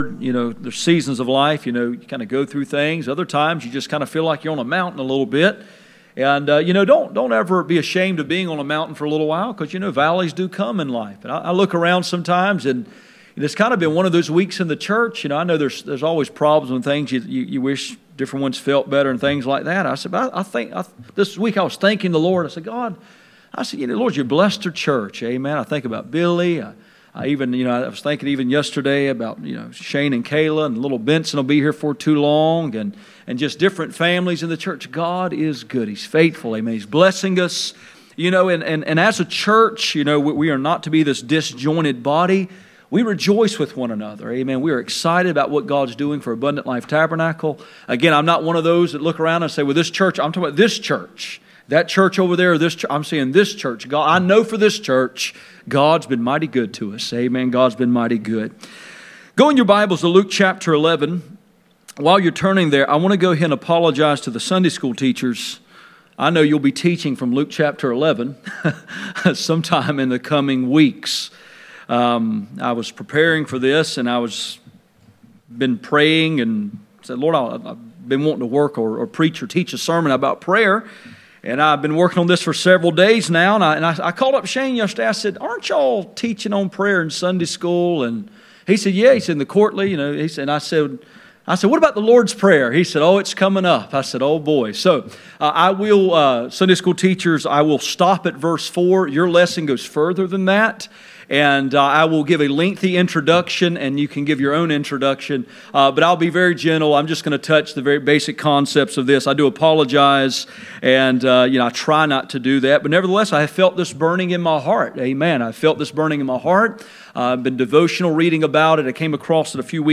Luke 11:1-4 Service Type: Sunday Morning %todo_render% « Controlling the Tongue